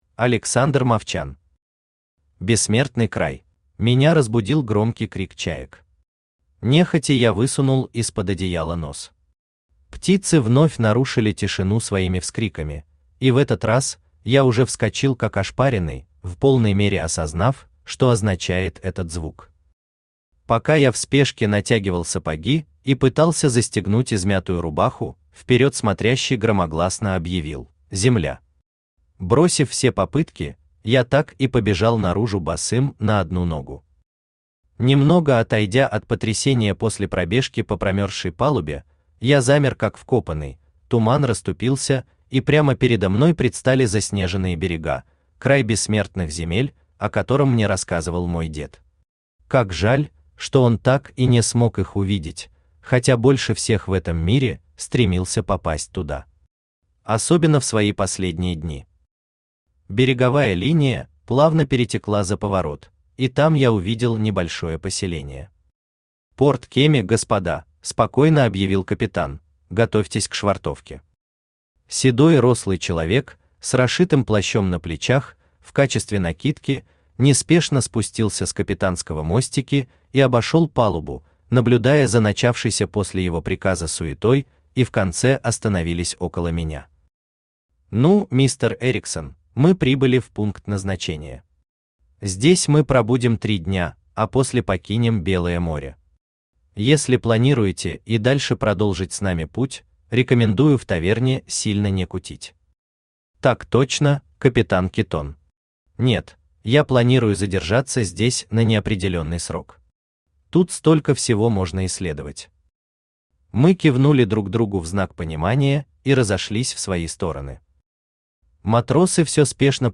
Аудиокнига Бессмертный край | Библиотека аудиокниг
Aудиокнига Бессмертный край Автор Александр Юрьевич Мовчан Читает аудиокнигу Авточтец ЛитРес.